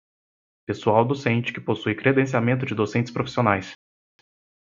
Pronounced as (IPA)
/kɾe.dẽ.si.aˈmẽ.tu/